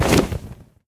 dragon_wings3.ogg